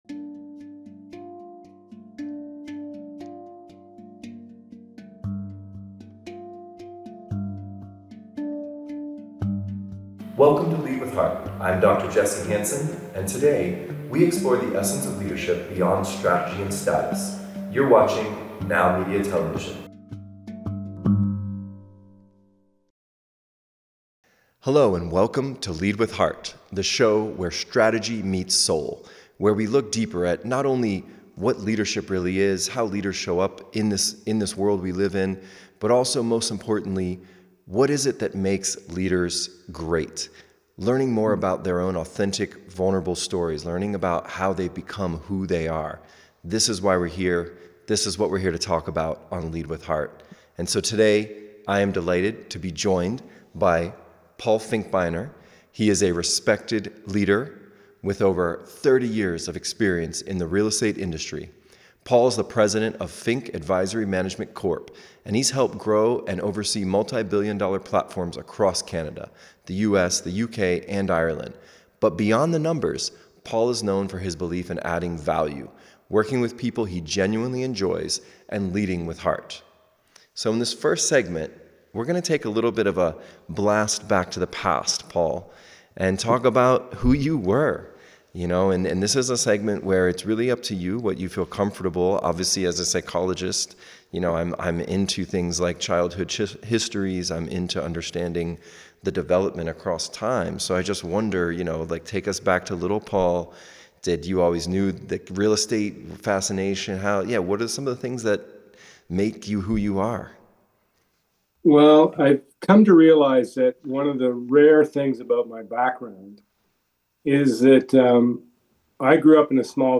Discover how early life experiences shape leadership style, why vulnerability builds trust, and how setbacks can become stepping stones to success. This conversation dives deep into personal growth, family values, and the mindset needed to lead with purpose in business and life.